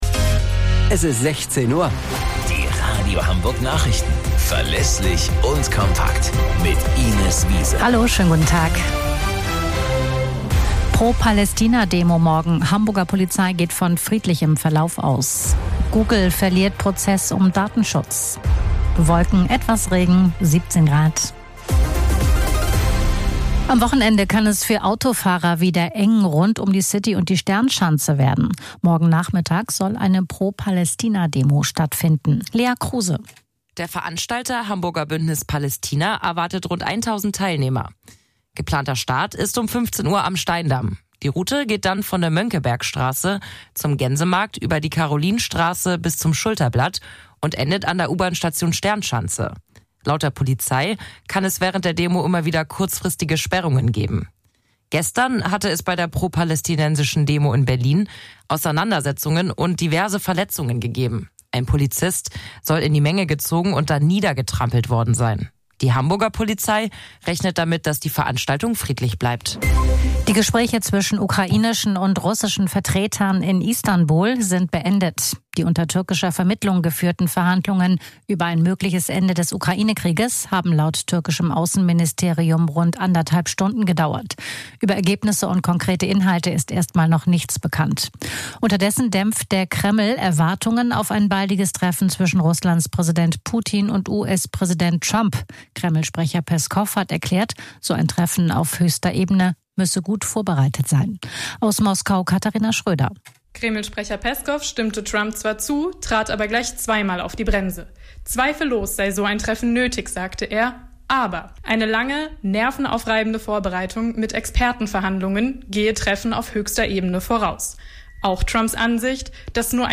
Radio Hamburg Nachrichten vom 16.05.2025 um 19 Uhr - 16.05.2025